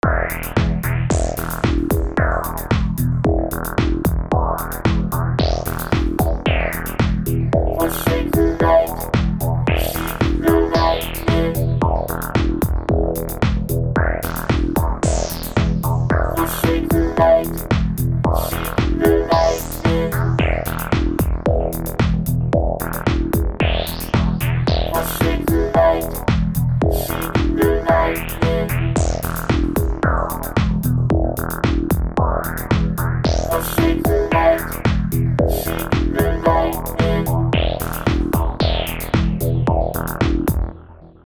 I was able to dial in the Vocoder sound that I wanted. Unlike the old days where I'd then make an entry in a notebook describing how each knob was set, I just save a preset for the vocoder and the synth that provides the carrier sound (the "Wasp" in the image).
record_vocoder_test.mp3